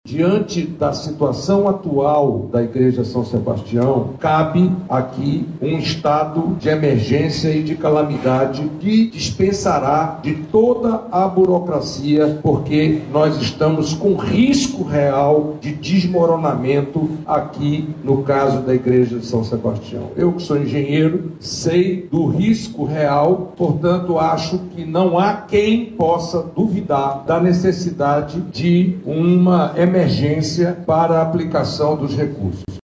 O senador do Amazonas, Eduardo Braga, que também esteve presente no evento, sugeriu a aplicação de um estado de emergência para agilizar a liberação de recursos públicos para a execução da segunda etapa da obra.